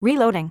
OCEFIAudio_en_Reloading.wav